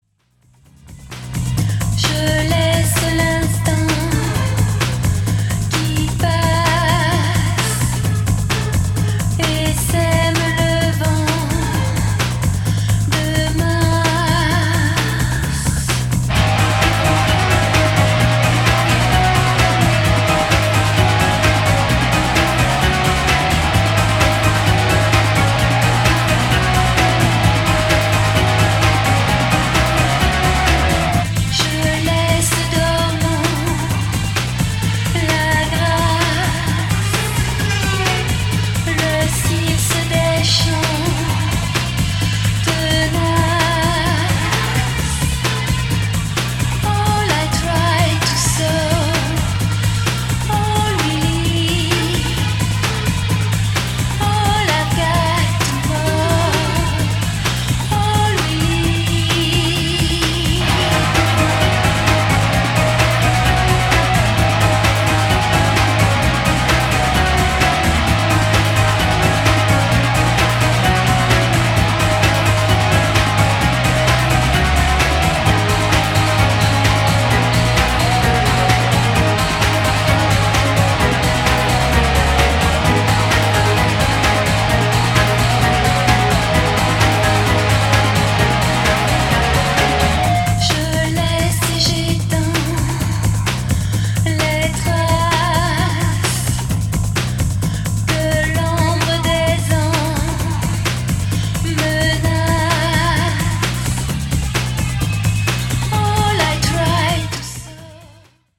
フランスのゴシック、ネオ・クラシカルバンド
エレクトロ・ロック、ニューウェーブファンにオススメです。
voices
cello
keyboards, voices
guitars
bass